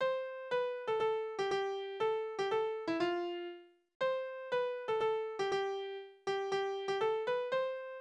Tonart: C-Dur
Taktart: 2/4
Tonumfang: große Sexte
Besetzung: vokal
Anmerkung: Polka